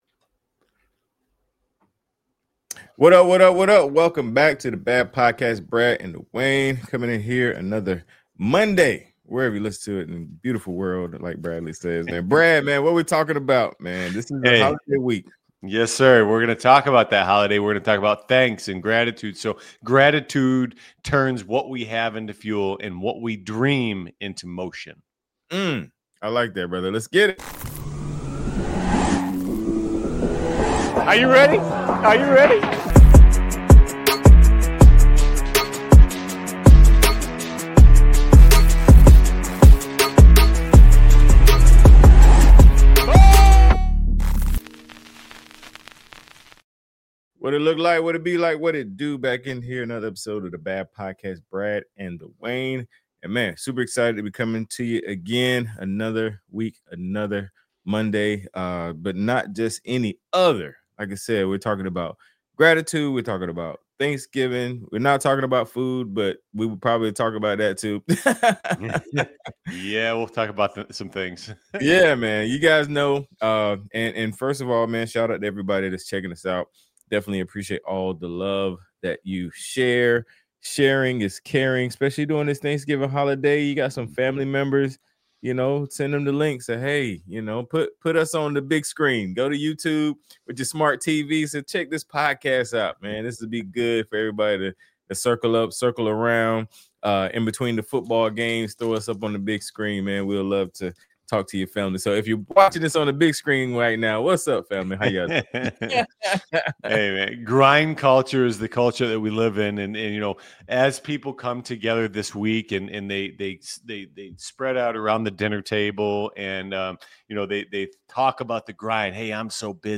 If you have been juggling family, work, fitness goals, or a changing routine, this is the conversation that pulls you back into focus.